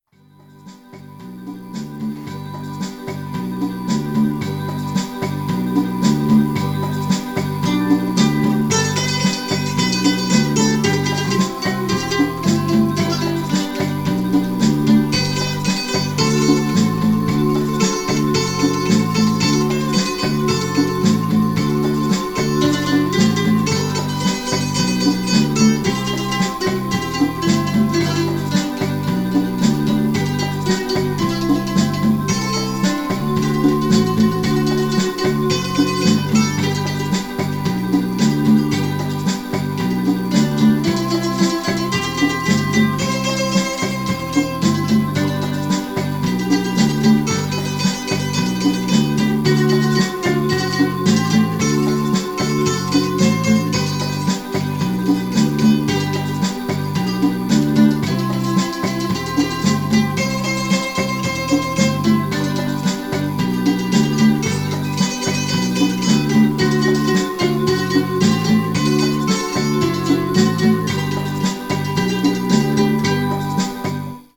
Hopp: egy mandolin!
Már annyiban gépi, hogy egy szintetizátor játsza az általam megadott akkordokat és ritmust,